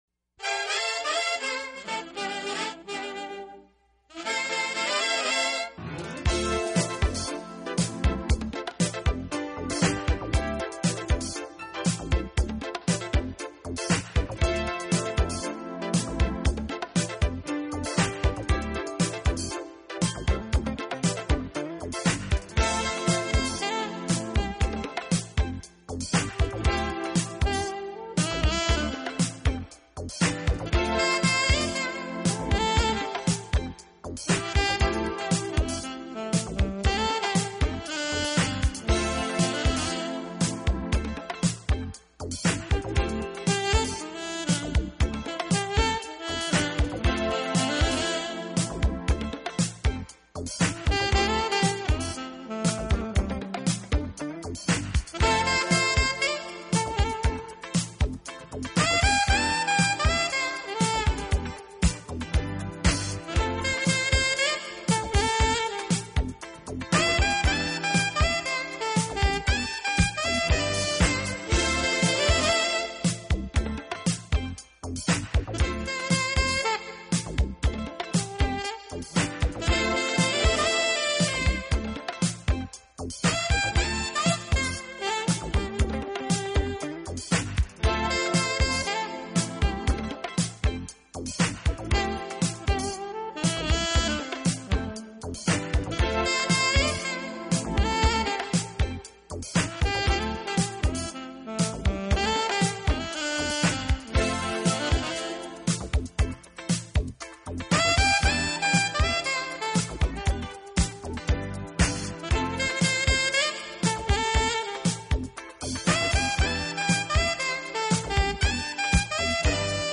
顶尖的技巧，绝对的大师，高雅别致的气质，真挚深情的音乐风格。